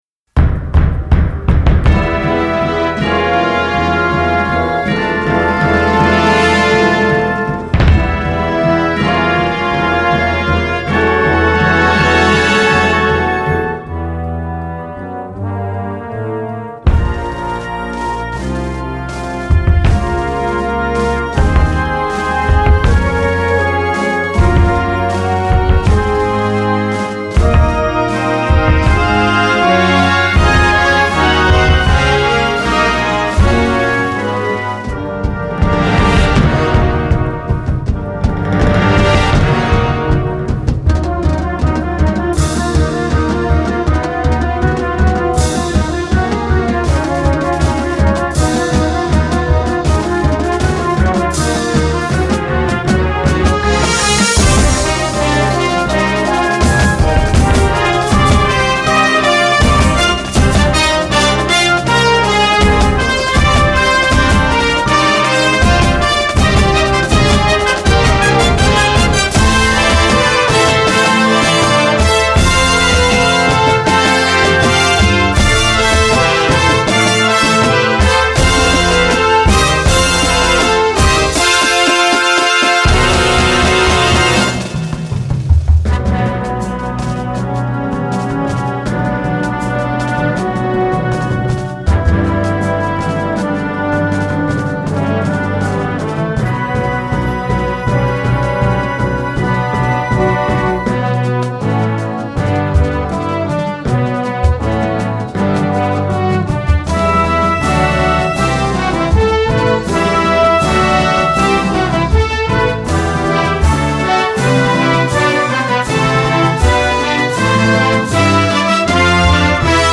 Gattung: Filmmusik
Marching-Band
Besetzung: Blasorchester